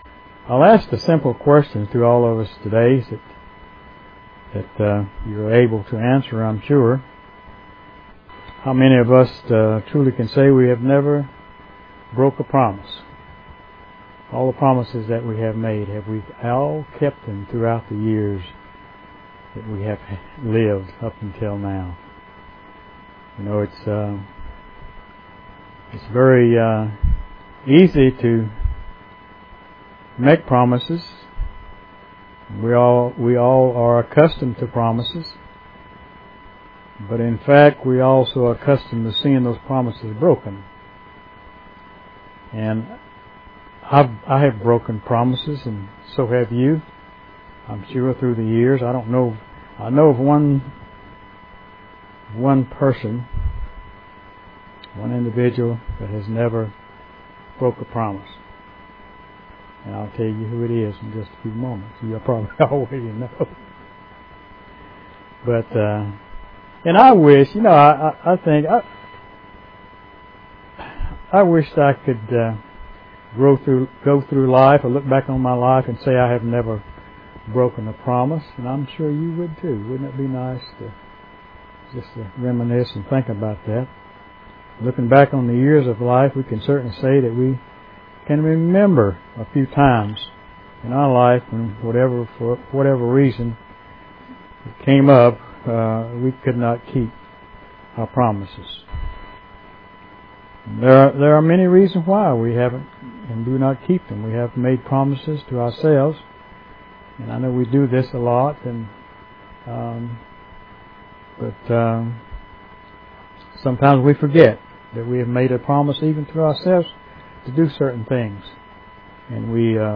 Given in Charlotte, NC
UCG Sermon